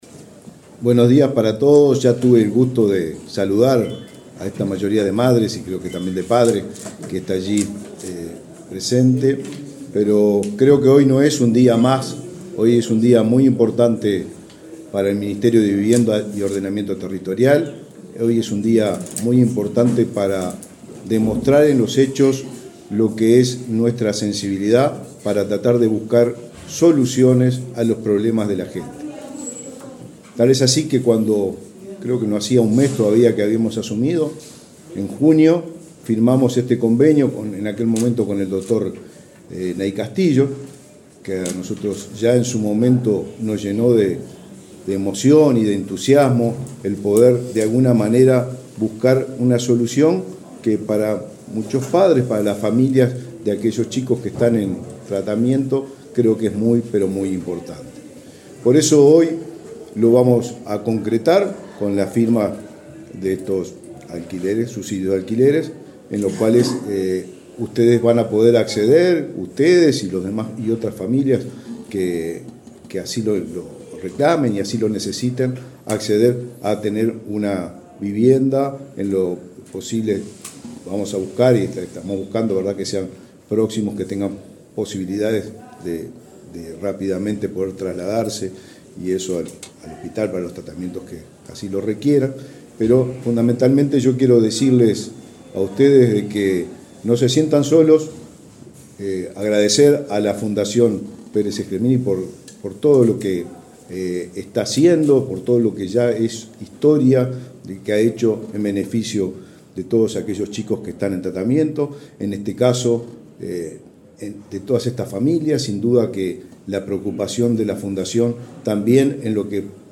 Palabras del ministro de Vivienda, Raúl Lozano
Este jueves 7, en el Ministerio de Vivienda, el titular de la cartera, Raúl Lozano, entregó subsidios de alquiler a siete familias con integrantes que